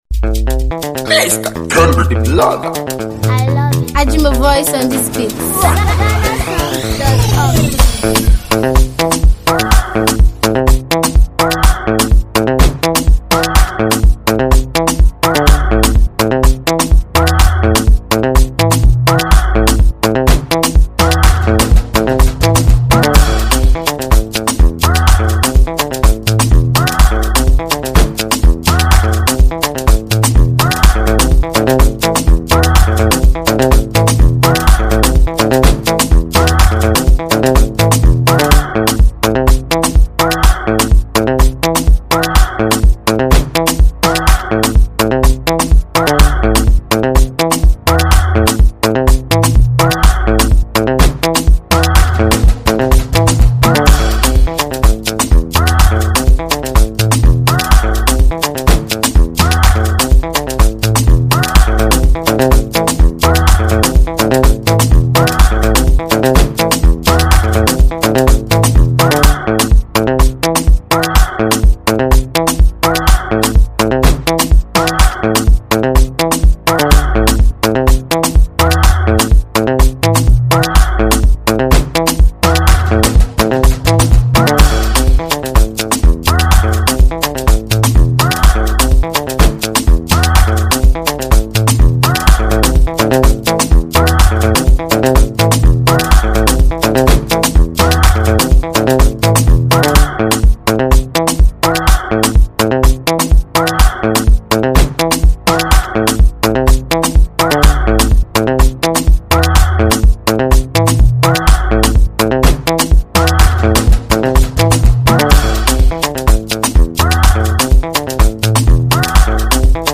an original trendy beat